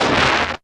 TEAR.wav